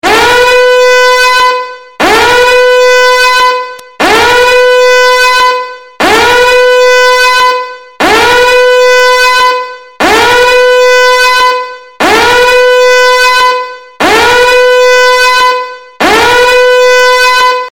alarm-sound_24661.mp3